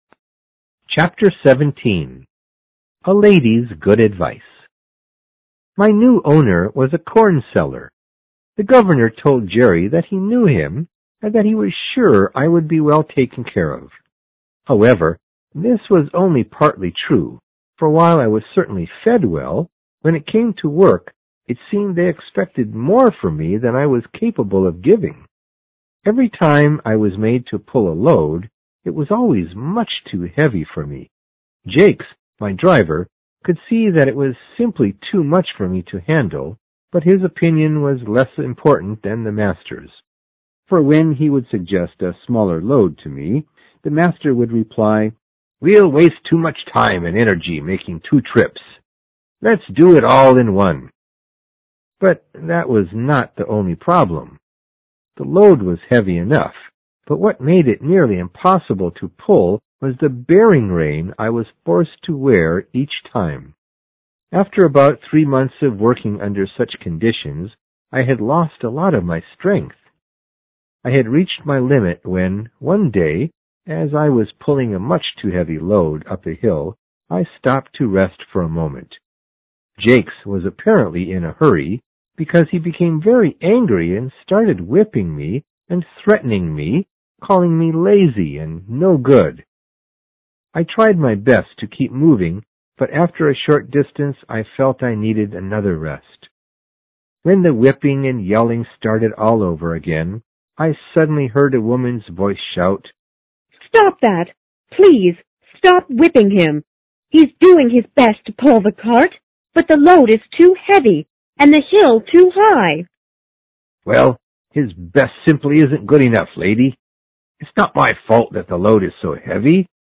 有声名著之黑骏马 Chapter17